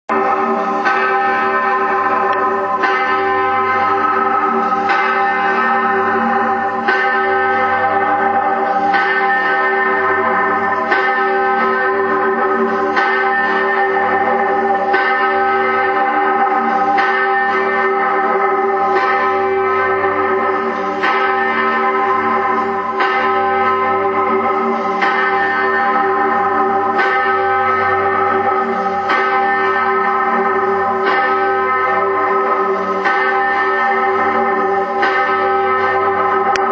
Hl Kreuz HerzJesuGlocke
Hl-Kreuz-HerzJesuGlocke.wma